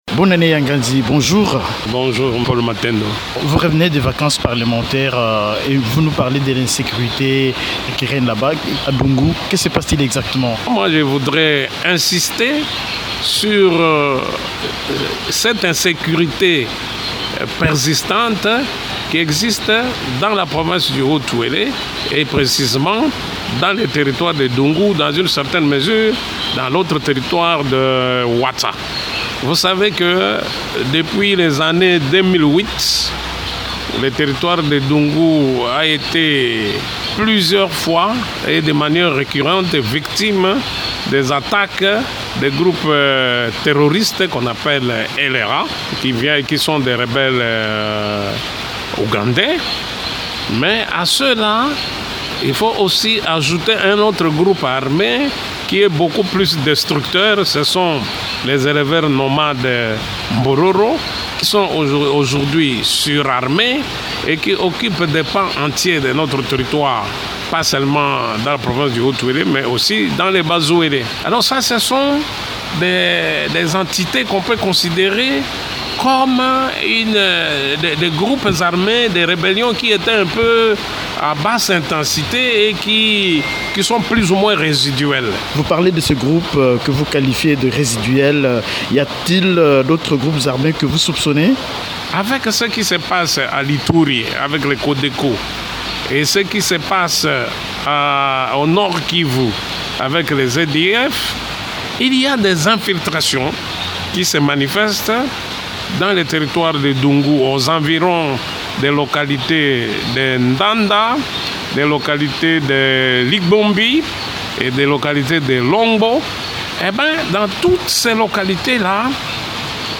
Invité de Radio Okapi, le député élu de ce territoire, Xavier-Bonane Yanganzi, cite entre autres les rebelles ougandais de LRA, les éleveurs Mbororo et d’autres groupes armés qui proviennent de l’Ituri et du Nord-Kivu.